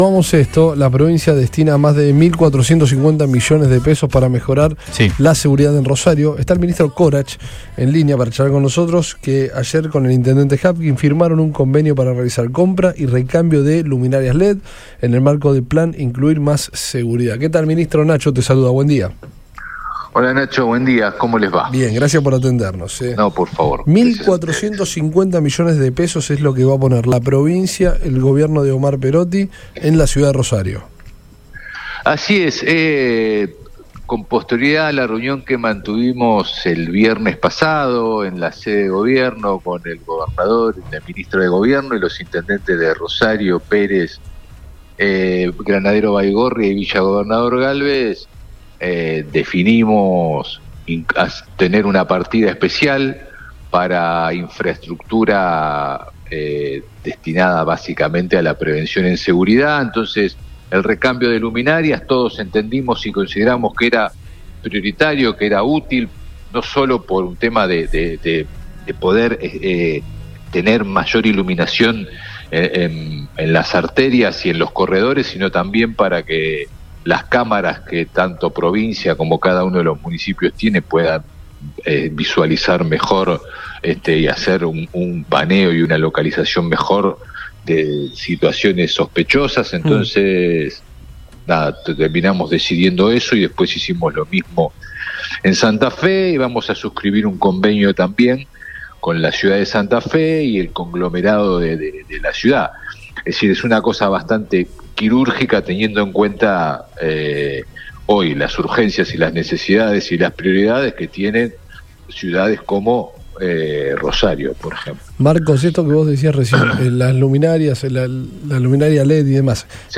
EN RADIO BOING
Marcos-Corach.mp3